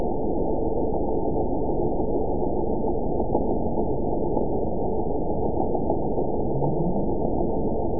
event 917075 date 03/18/23 time 19:48:34 GMT (2 years, 1 month ago) score 9.57 location TSS-AB04 detected by nrw target species NRW annotations +NRW Spectrogram: Frequency (kHz) vs. Time (s) audio not available .wav